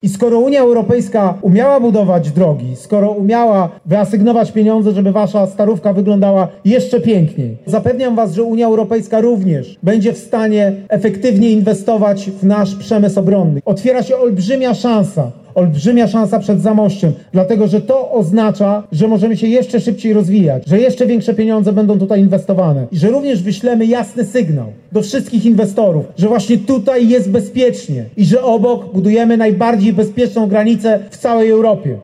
O potrzebie rozwijania przemysłu obronnego i współdziałaniu na tym polu ze strukturami unijnymi mówił w niedzielę (04.05) podczas wiecu w Zamościu Rafał Trzaskowski, kandydat Koalicji Obywatelskiej na prezydenta RP.